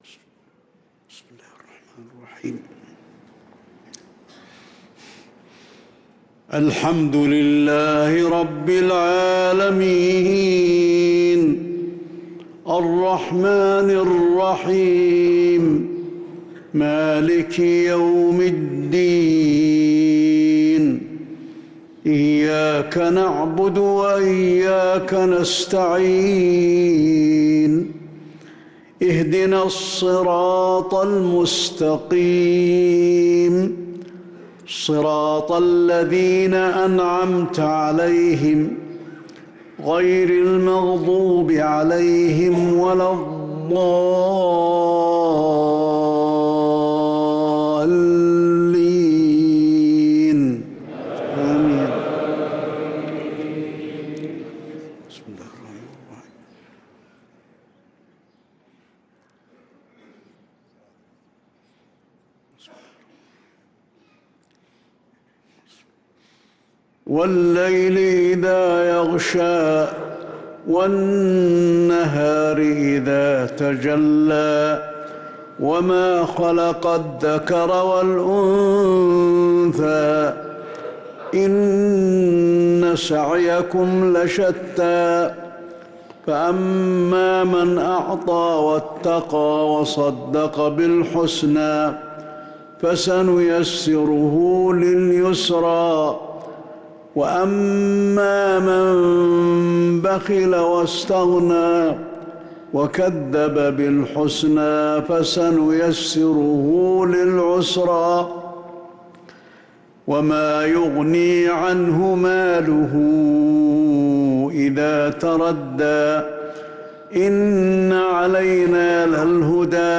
صلاة العشاء للقارئ علي الحذيفي 8 شعبان 1445 هـ
تِلَاوَات الْحَرَمَيْن .